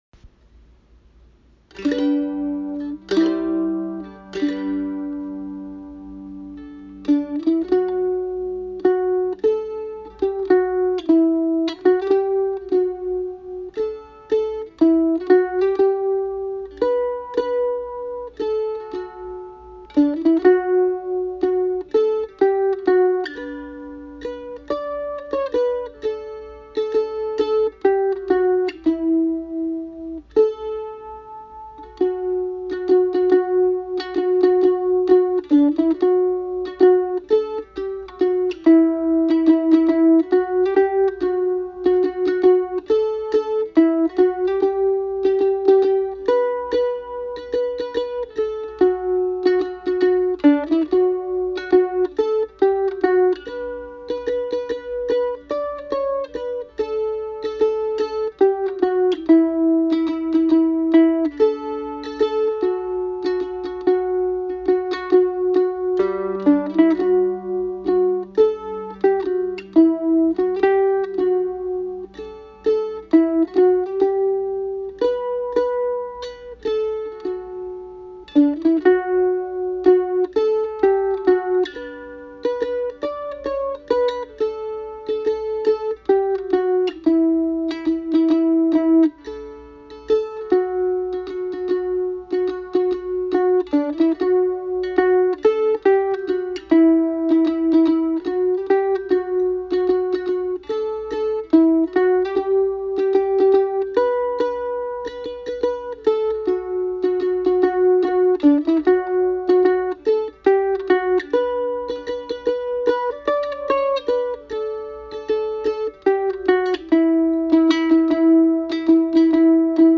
Played on my Ratliff mandolin
Custom Ratliff Mandolin
Just-as-I-am-mandolin-.mp3